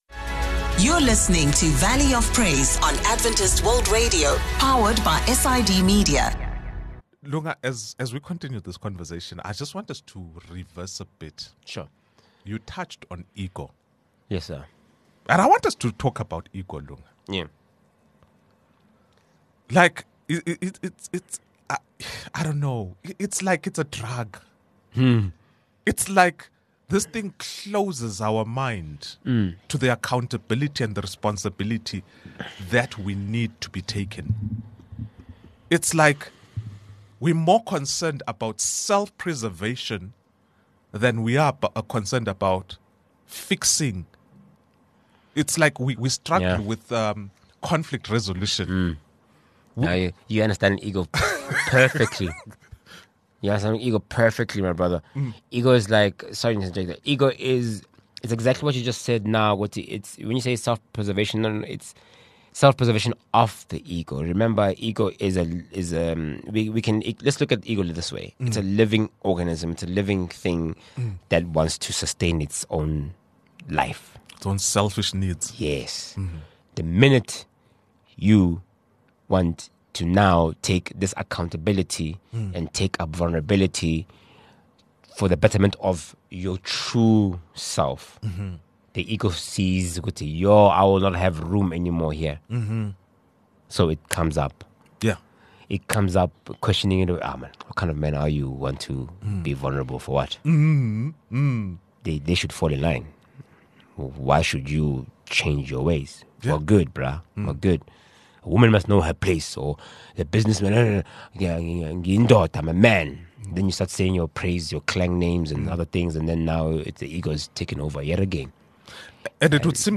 Discover how faith, vulnerability, and community can guide men to healing, purpose, and transformation. A powerful conversation on letting God lead the way!